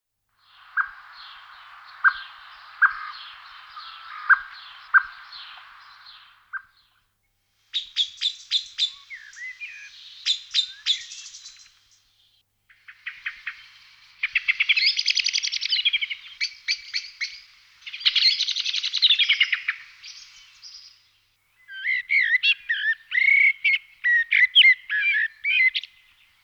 Turdus merula
merlo.mp3